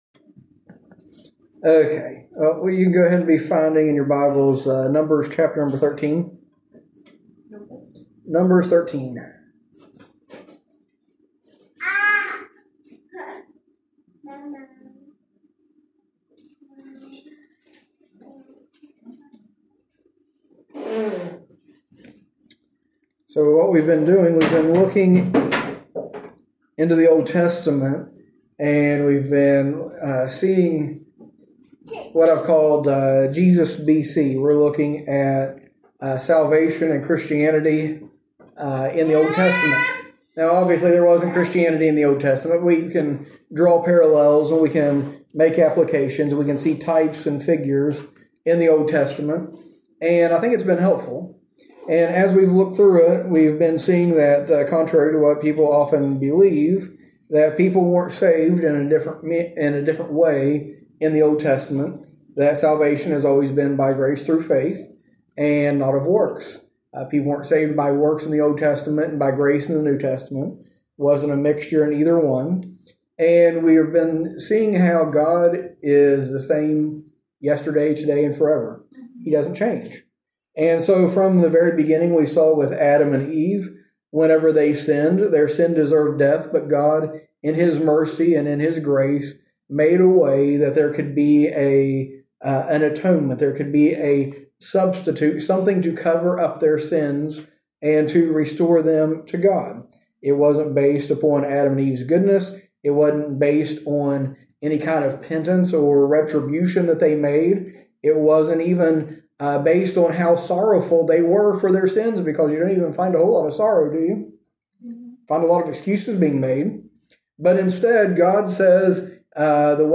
A message from the series "Jesus BC."